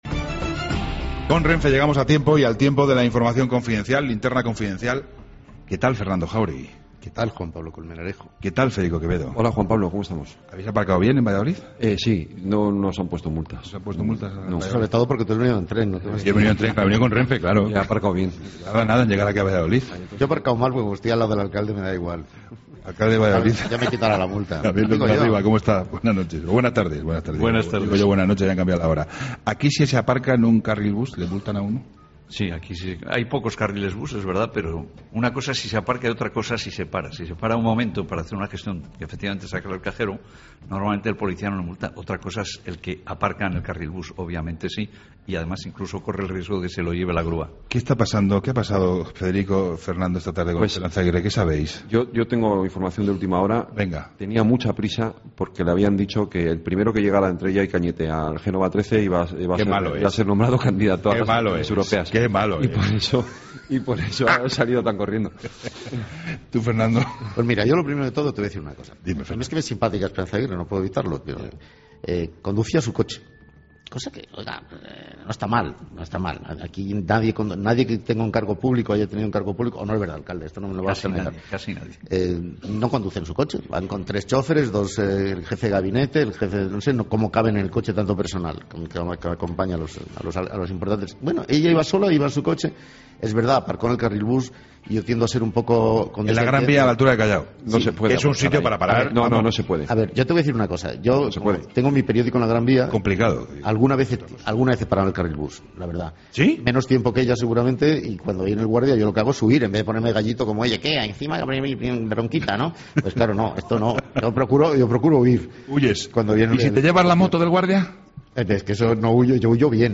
Entrevista Javier León de la Riva